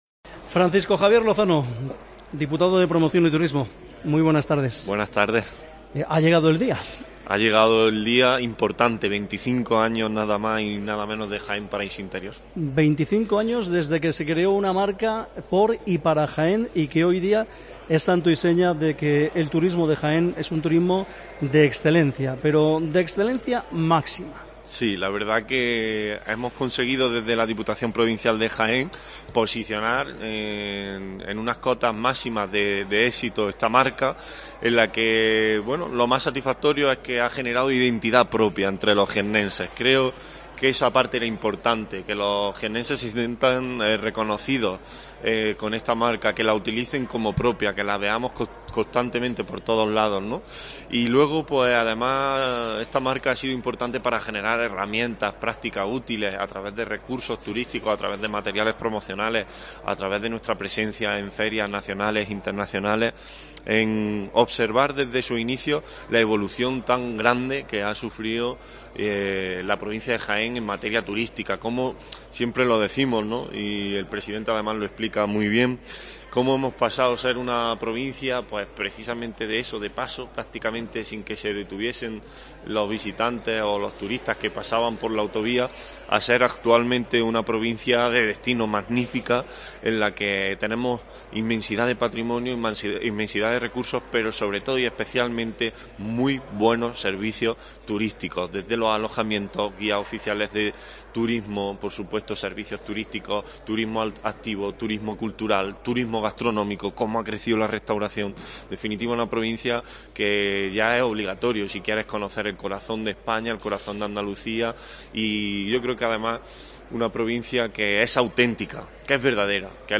Hoy en COPE charlamos con Francisco Javier Lozano, Diputado de Promoción y Turismo